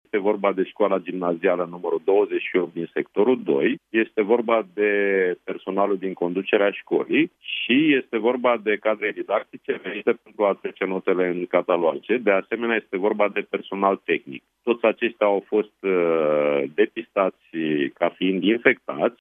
Focarul a fost descoperit la o școală generală din Sectorul 2 al Capitalei, unde cadrele didactice au venit să treacă notele de final de semestru în catalog, spune, la Europa FM, ministrul Educației, Sorin Cîmpeanu.